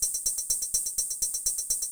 PERCUSSN007_DISCO_125_X_SC3.wav